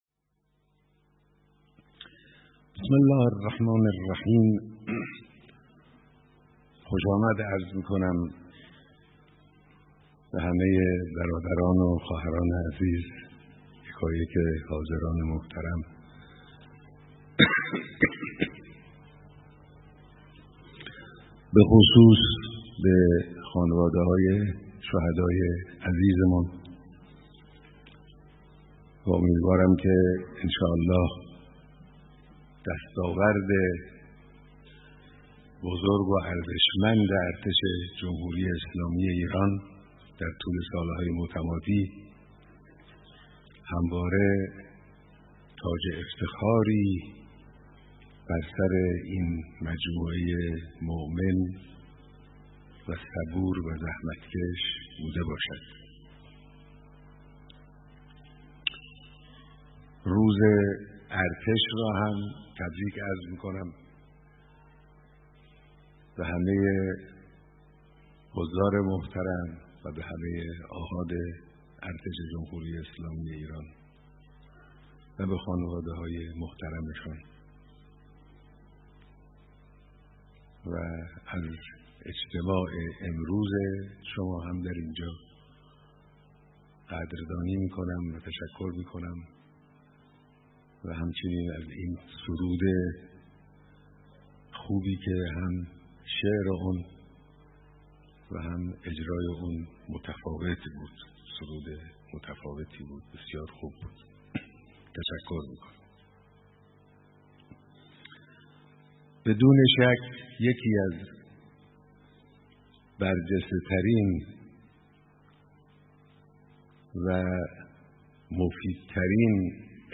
به گزارش سرویس سیاسی پایگاه 598 به نقل از پایگاه اطلاع‌رسانی دفتر مقام معظم رهبری حضرت آیت الله خامنه ای رهبر معظم انقلاب اسلامی صبح امروز (یکشنبه) در دیدار جمعی از فرماندهان، کارکنان و خانواده های شهدای ارتش، نیروهای مسلح را به حفظ و تقویت بصیرت و جهت گیریهای دینی و انقلابی، و افزایش توان دفاعی و تسلیحاتی و آمادگی روحی توصیه موکد کردند و گفتند: جمهوری اسلامی ایران هیچگاه تهدیدی برای منطقه و کشورهای همسایه نبوده و نخواهد بود اما در برابر هر گونه تعرضی کاملا مقتدرانه عمل خواهد کرد.